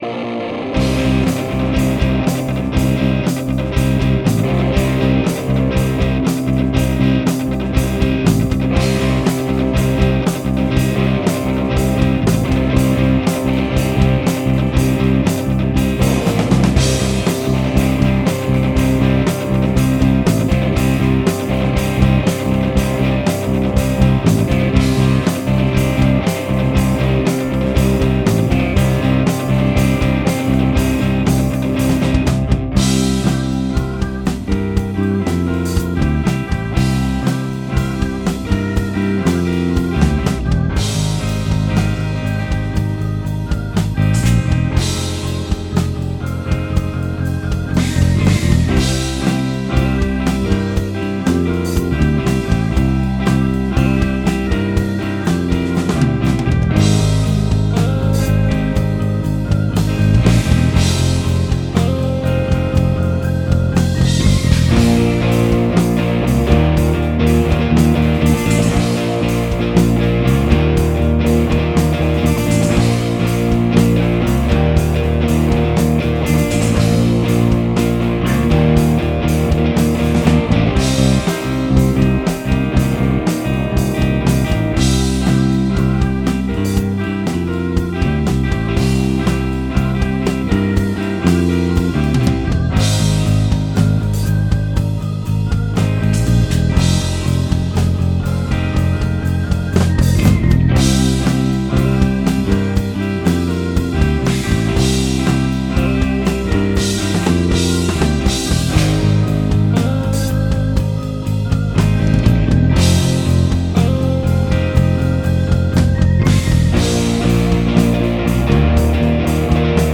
Live at Orange House Munich Germany